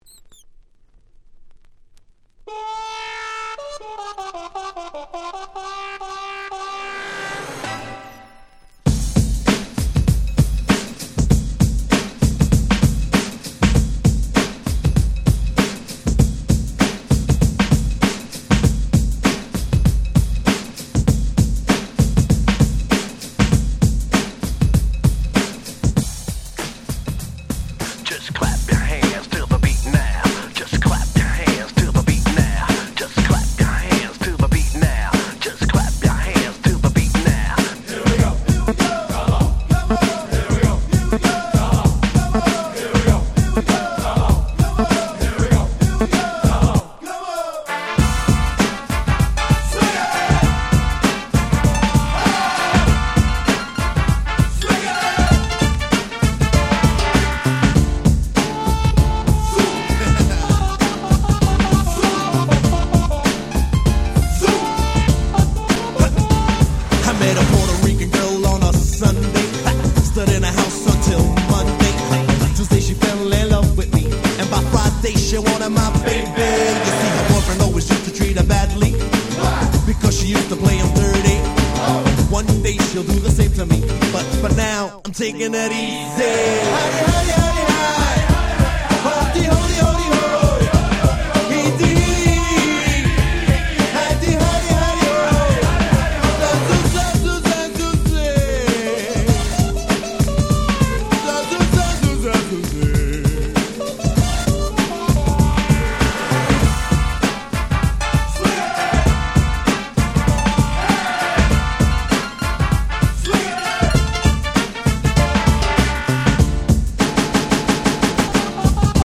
93' Smash Hit Hip Hop !!